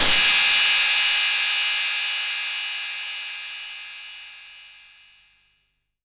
808CY_3_TapeSat.wav